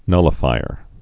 (nŭlə-fīər)